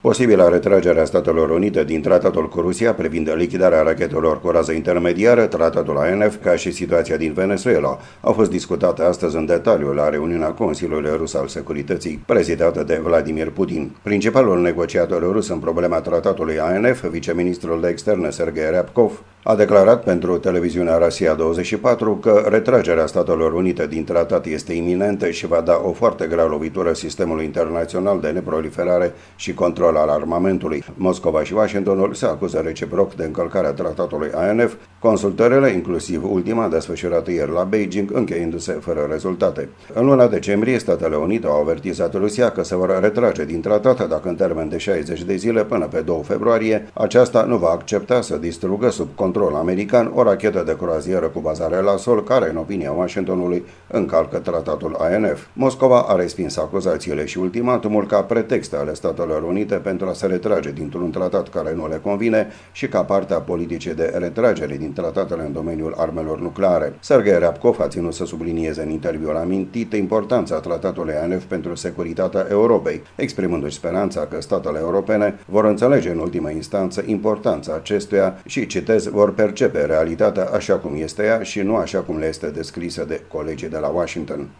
O corespondenţă